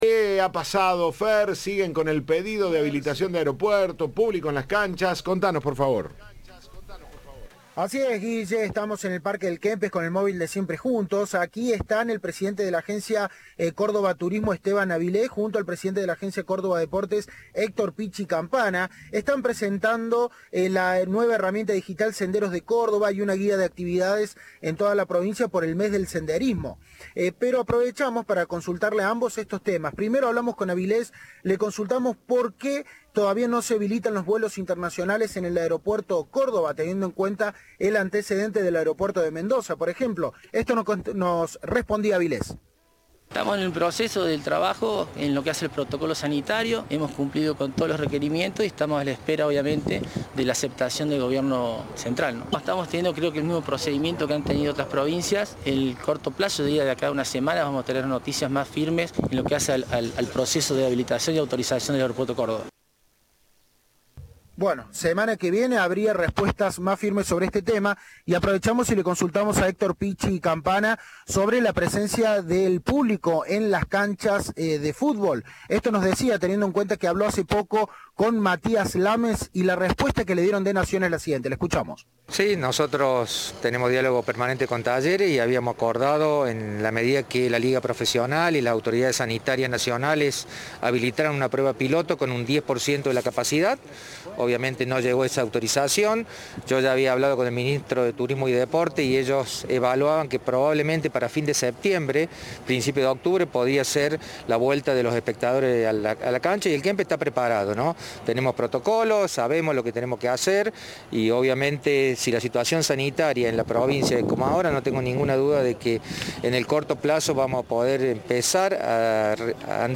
Esteban Avilés, presidente de la Agencia Córdoba Turismo, dijo a Cadena 3 que están a la espera de la aceptación de protocolos sanitarios por parte del Gobierno central.
Informe